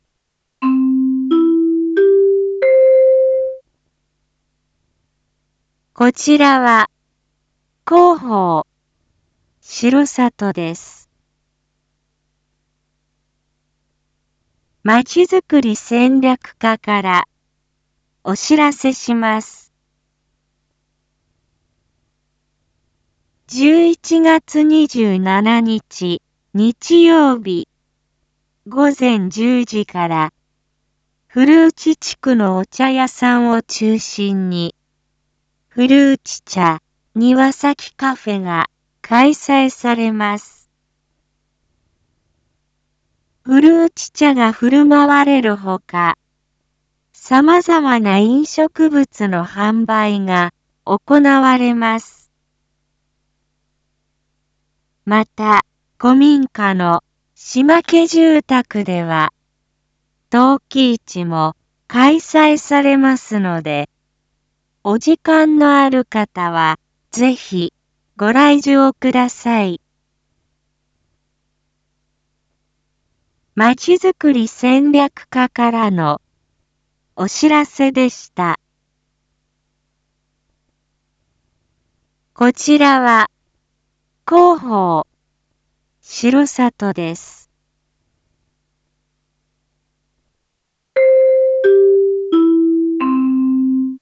一般放送情報
Back Home 一般放送情報 音声放送 再生 一般放送情報 登録日時：2022-11-25 19:01:36 タイトル：R4.11.25 19時放送分 インフォメーション：こちらは、広報しろさとです。